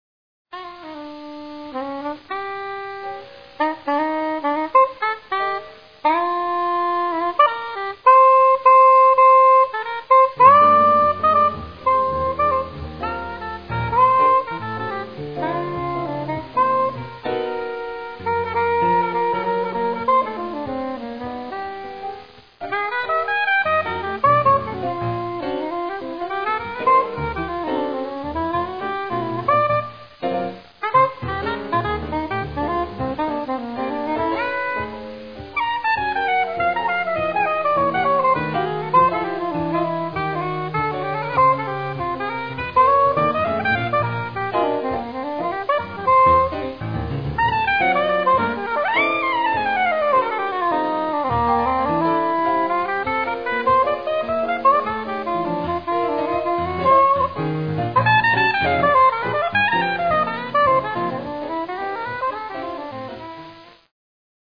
duets with pianists